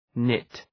Shkrimi fonetik {nıt}